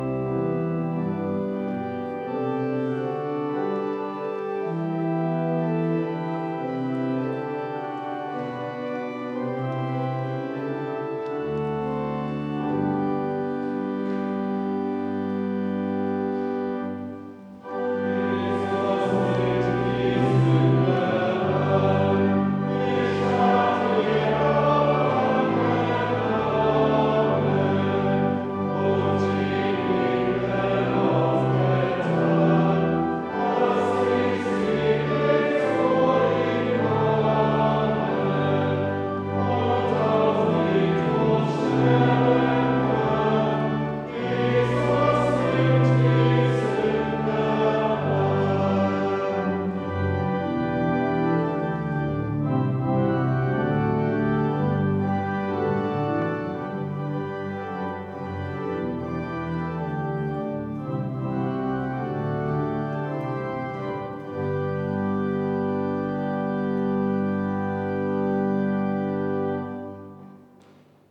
Audiomitschnitt unseres Gottesdienstes vom 2. Sonntag nach Ostern 2025.